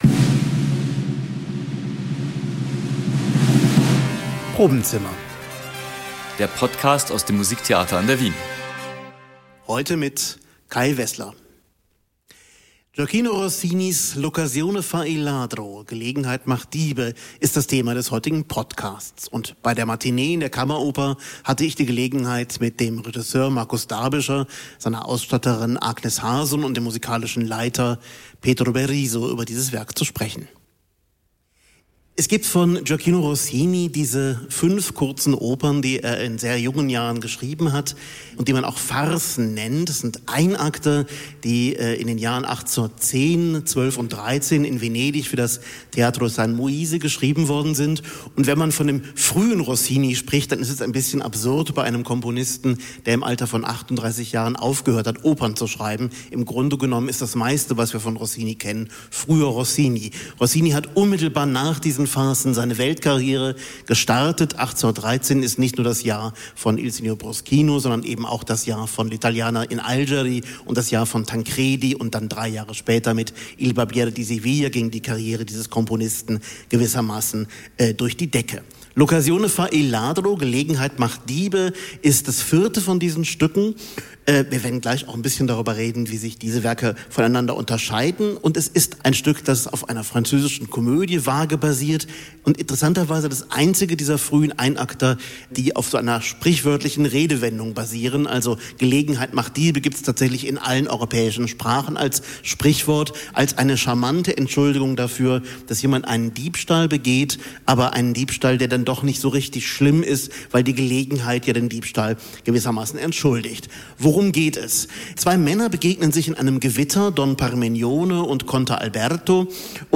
Das Ensemble macht auch bereits musikalisch Lust auf diesen kurzweiligen Abend.